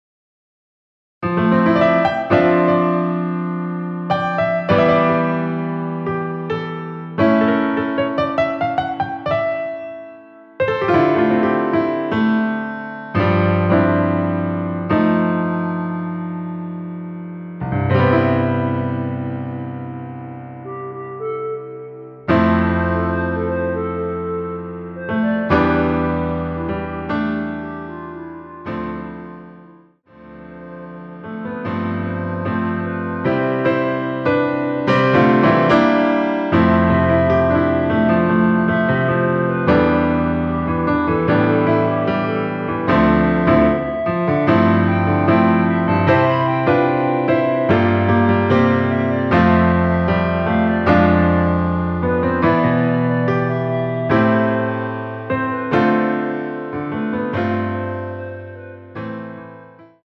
멜로디 MR이란
앞부분30초, 뒷부분30초씩 편집해서 올려 드리고 있습니다.
중간에 음이 끈어지고 다시 나오는 이유는